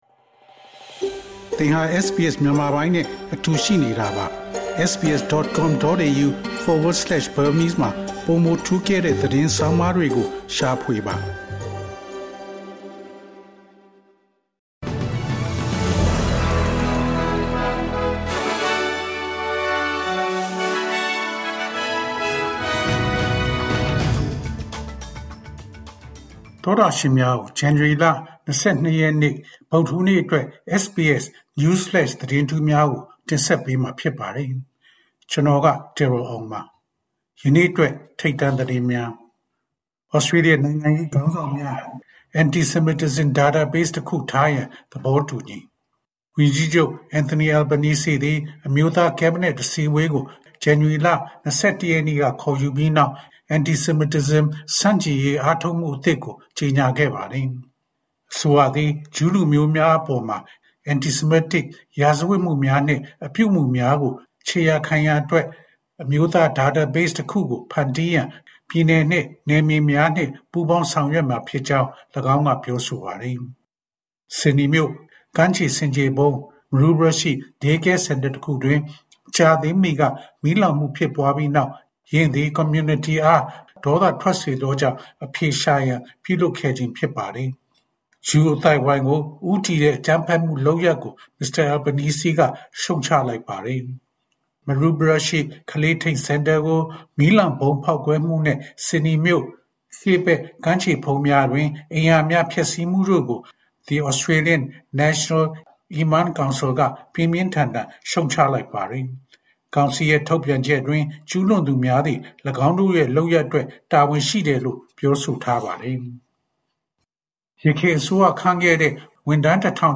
ဇန်နဝါရီလ ၂၂ ရက် တနင်္လာနေ့ SBS Burmese News Flash သတင်းများ။